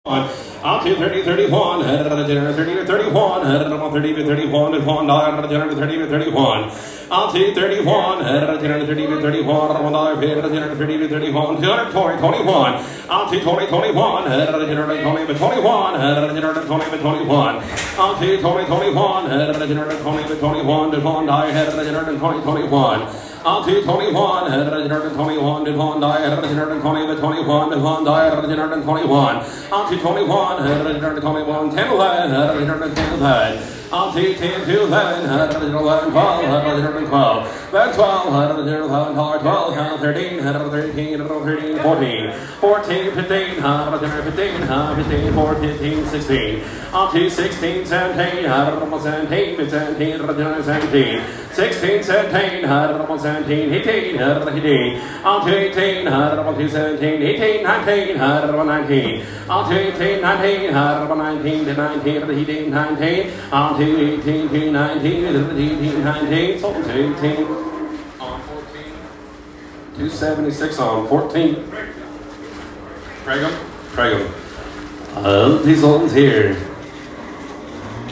The Livestock Auction
And finally I roll into Torrington, Wyoming for my livestock auction, the largest in Wyoming.
All while maintaining the singsong that drives the bidding forward.
I made many recordings of two auctioneers.
At the end you hear who bought the lot: 276 on 14. The auctioneers do have different styles.
auctioneer34.m4a